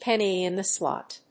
アクセントpénny‐in‐the‐slót